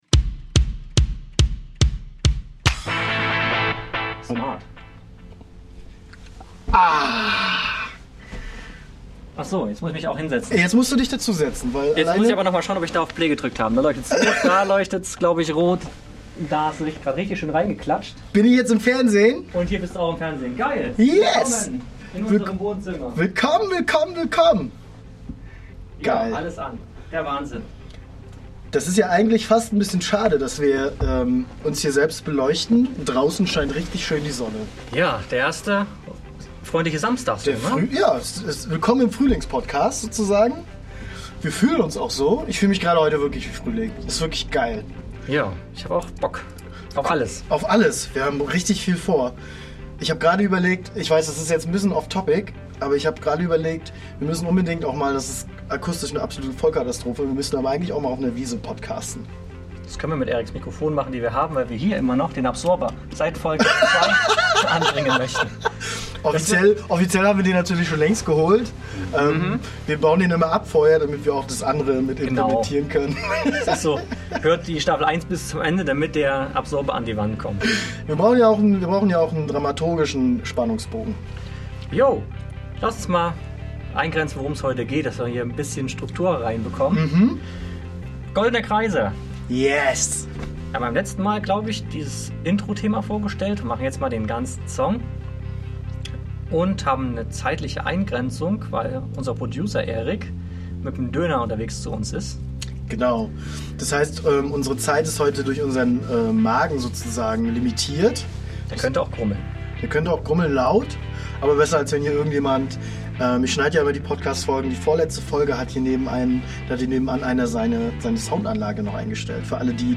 Dieses Mal mit Gitarre in der Hand und Geschichten aus dem letzten Jahrzehnt. Wir erzählen in dieser Folge, wie Goldene Kreise entstanden ist.